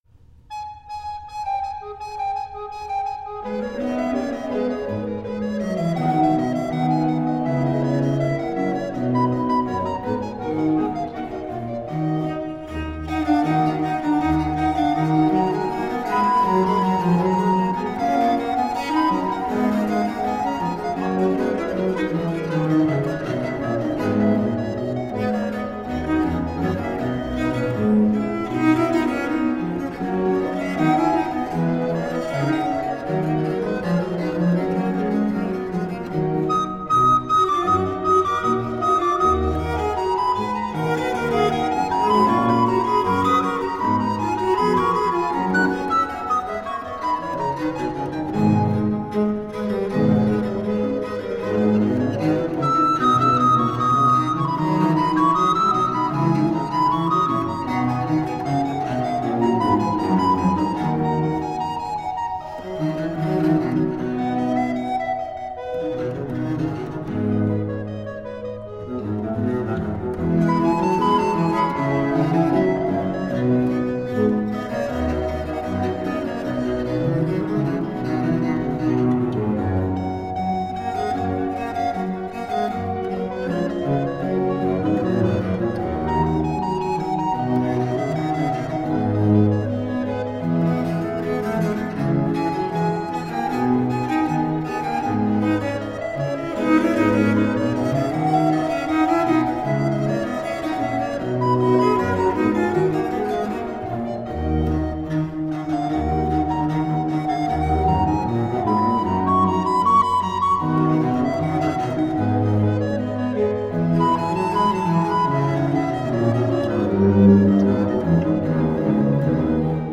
Richly textured 17th and 18th century cello and recorder.
It's a richly textured work, but always light on its feet.
Classical, Baroque, Instrumental, Cello
Harpsichord
Organ
Viola da Gamba